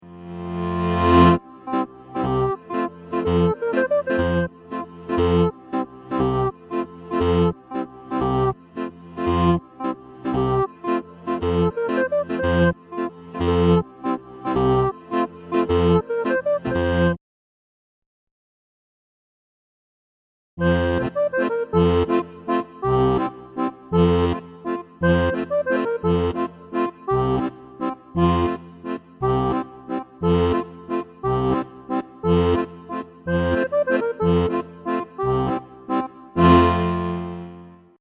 Den letzten Basston habe ich nur für den Abschluss des Rätsels eingefügt, er gehört nicht zum Lied.